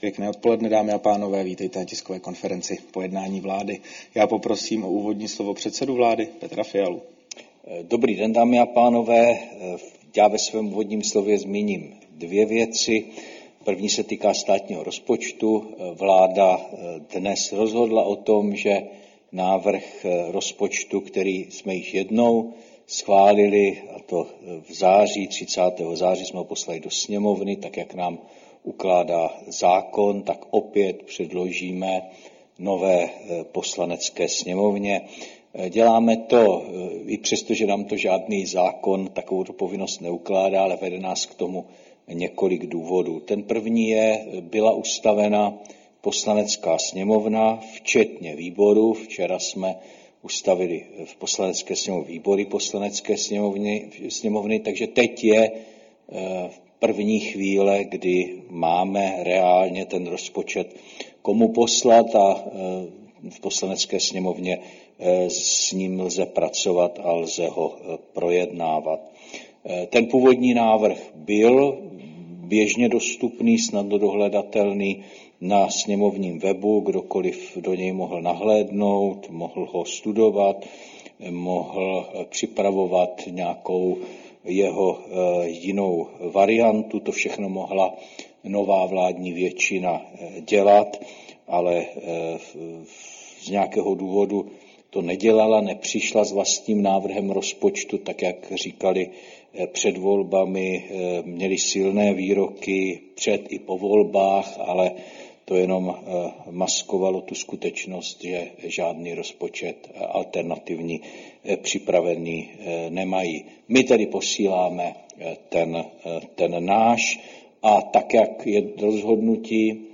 Tisková konference po jednání vlády, 12. listopadu 2025